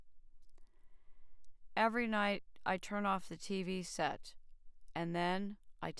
emotional-speech
EARS-EMO-OpenACE / p103 /emo_neutral_freeform /reference.wav